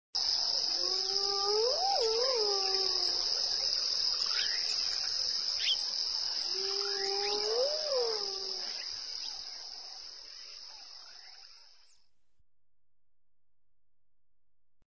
Add some hyena sound.
Hyena.mp3